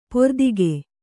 ♪ pordige